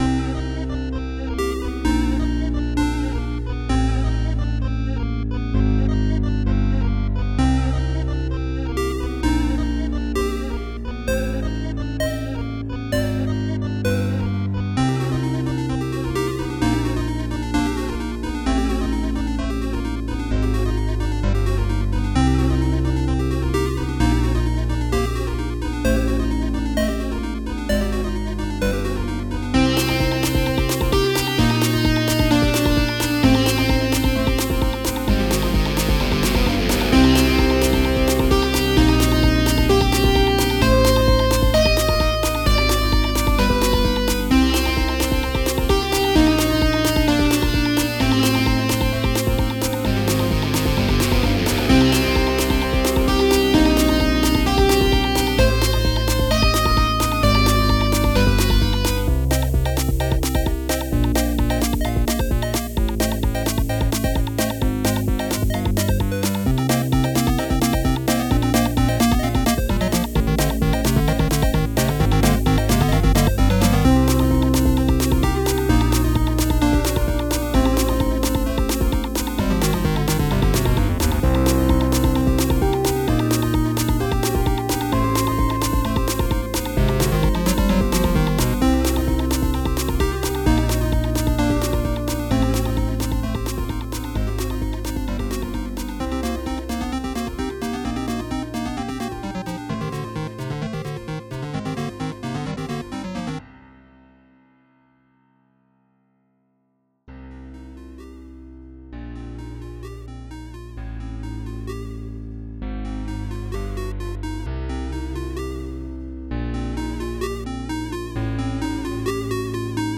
video game track
Game Music